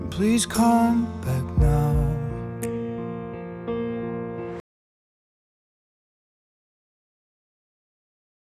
1-man.WAV